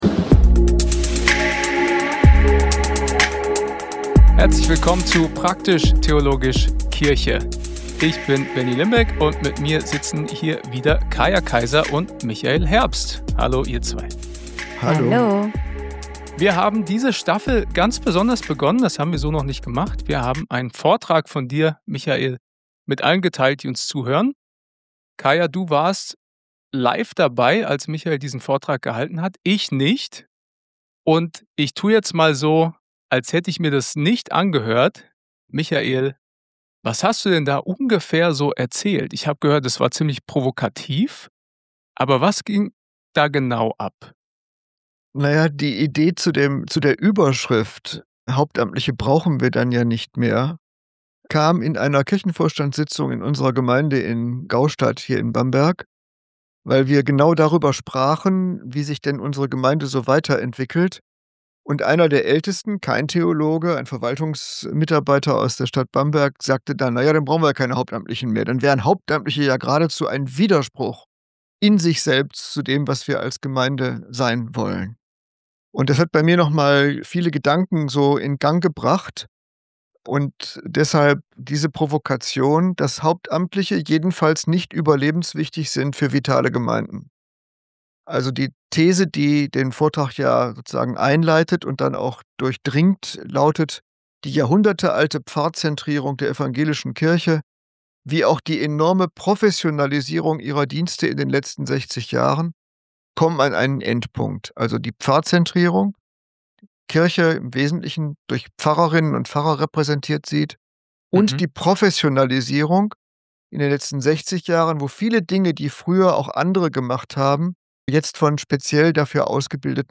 Die versprochene Q&A-Folge!